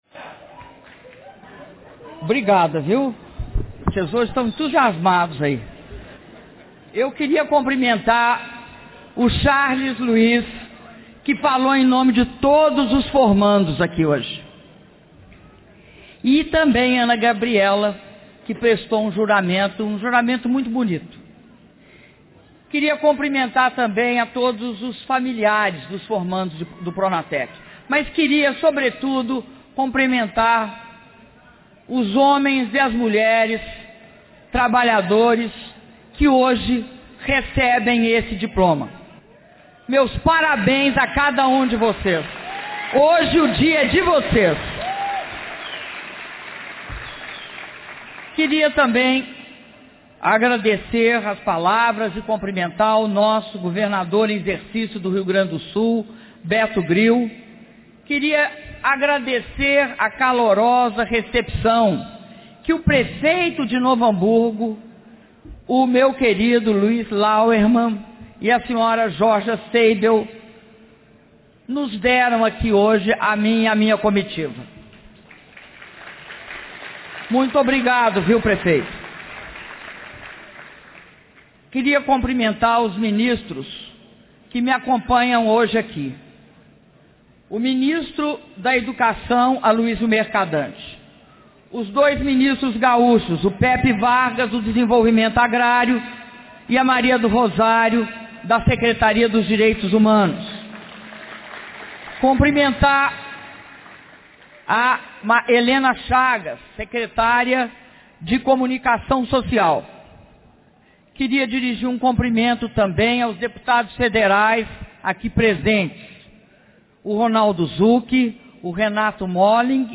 Áudio do discurso da Presidenta da República, Dilma Rousseff, na cerimônia de formatura de alunos do Pronatec - Novo Hamburgo/RS (22min58s)